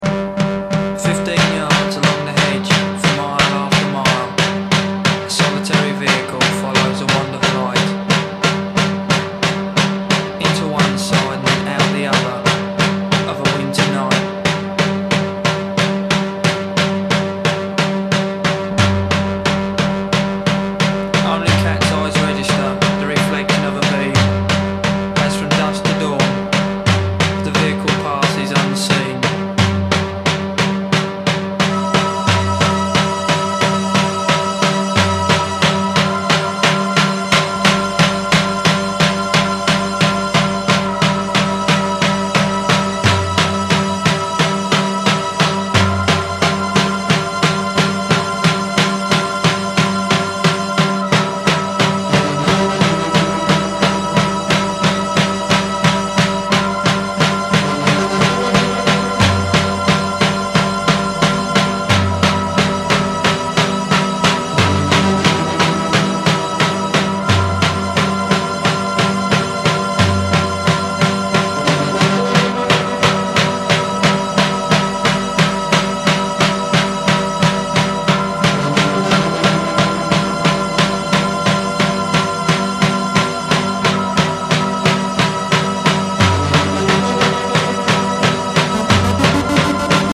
cult isolationist synth masterpiece